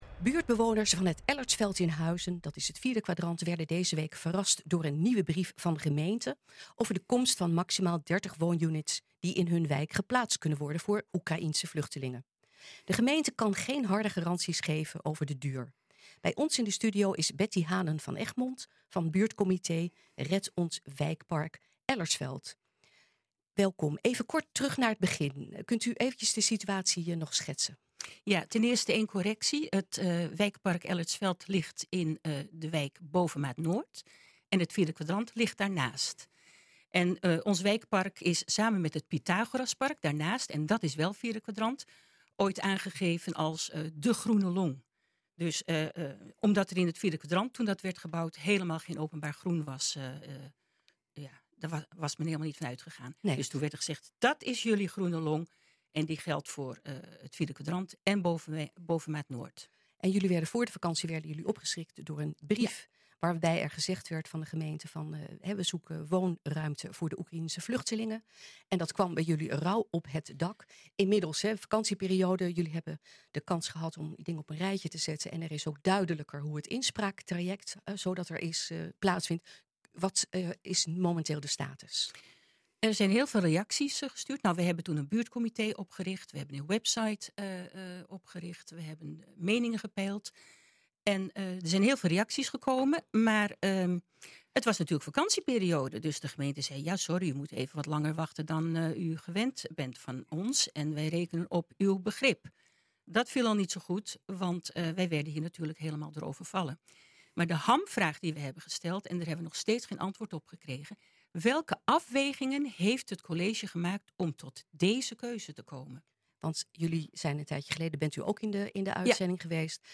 9 september luister hier het radio interview ‘buurt schrikt van 2e brief van gemeente over Ellertsveld’
NH-Gooi-Gemist-Buurt-schrikt-van-tweede-brief-gemeente-over-Ellertsveld.mp3